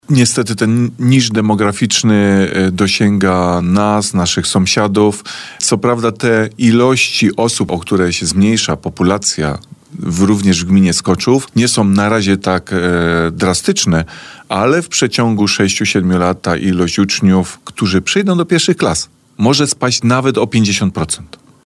Niestety ten niż demograficzny dosięga nas i naszych sąsiadów – komentował dziś w Radiu Bielsko dane demograficzne burmistrz Skoczowa Rajmund Dedio.